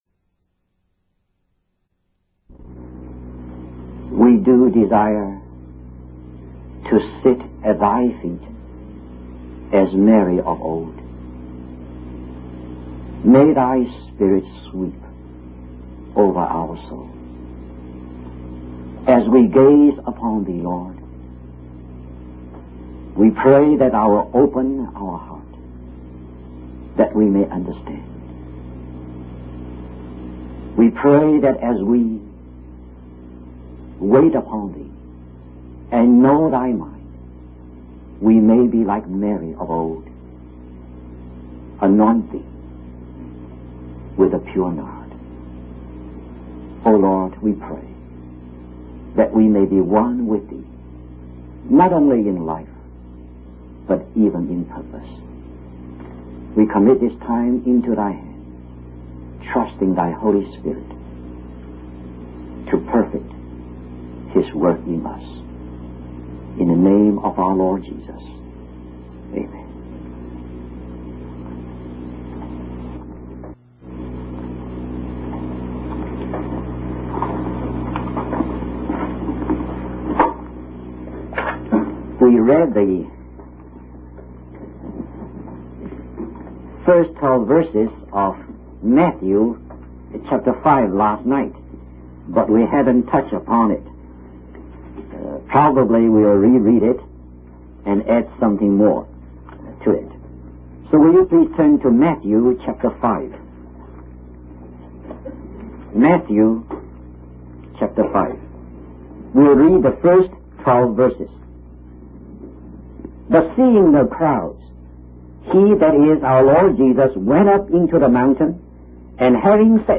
In this sermon, the preacher discusses the parable of the woman who mixed leaven into three measures of meal. He explains that traditionally, this parable is interpreted as a representation of the growth of Christianity.